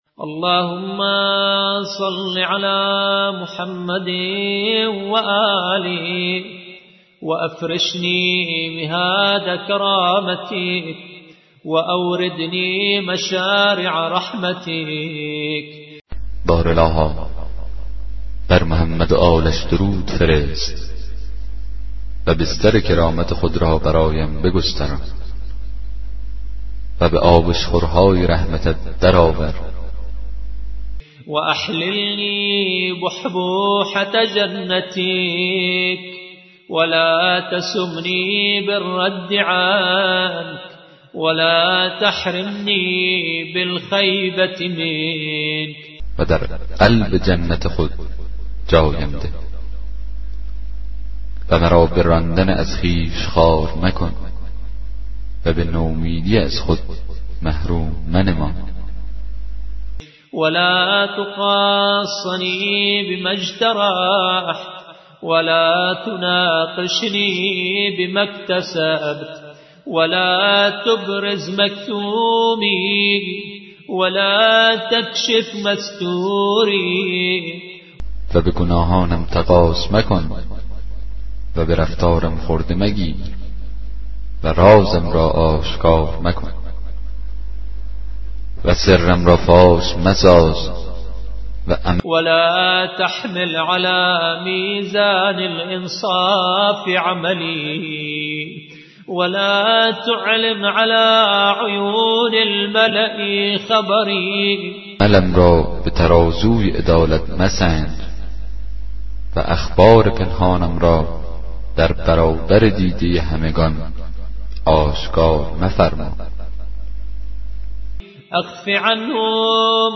کتاب صوتی دعای 41 صحیفه سجادیه